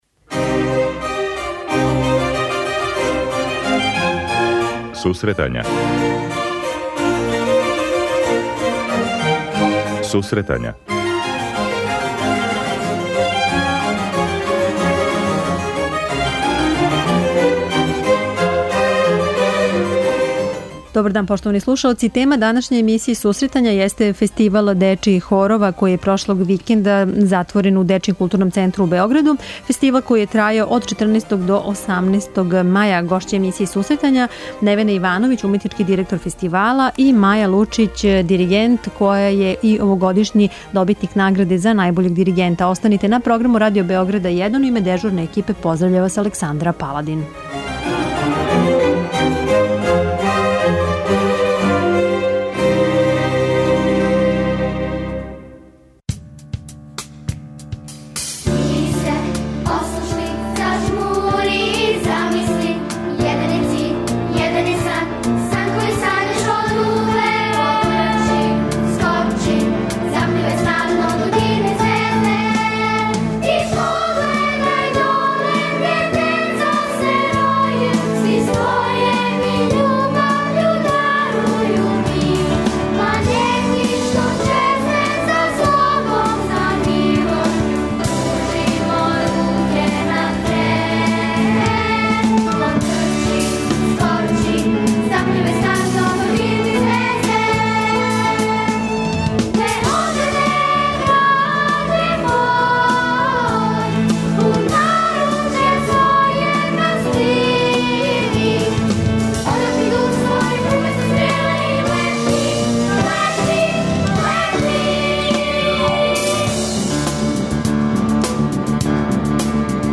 преузми : 52.37 MB Сусретања Autor: Музичка редакција Емисија за оне који воле уметничку музику.